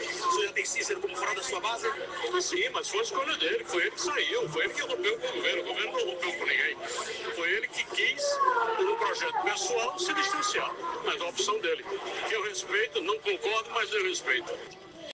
O governador João Azevêdo (PSB) afirmou, durante entrevista nesta segunda-feira (08), que o rompimento político com o prefeito de João Pessoa, Cícero Lucena (PP), partiu exclusivamente do gestor municipal. De acordo com informações veiculados na rádio Correio 98 FM, João apontou escolhas pessoais do gestor municipal como o principal motivo do afastamento.